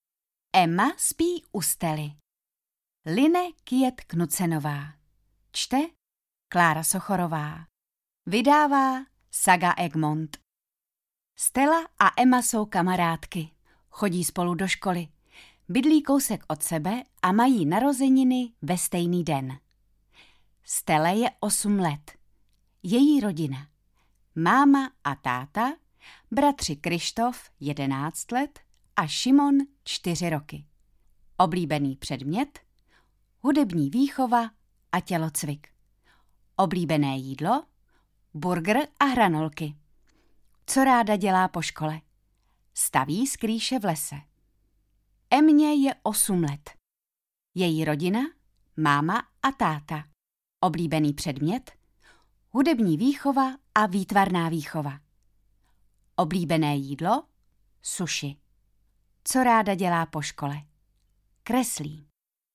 Ema spí u Stelly audiokniha
Ukázka z knihy